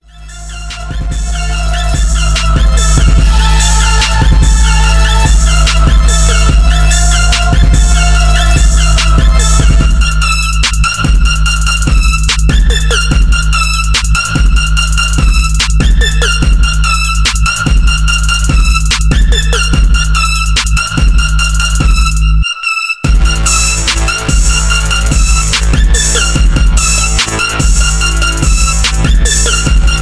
Fresh New Beat